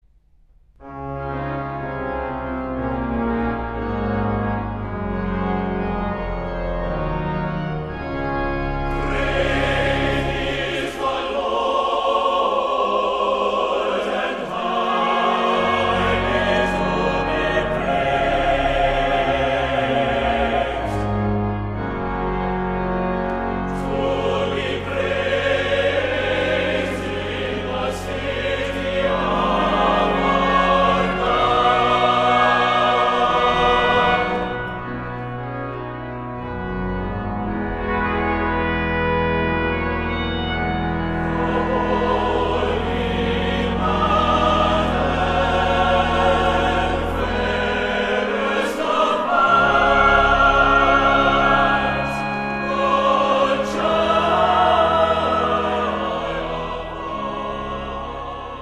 *Difficult, yet skillfully written organ part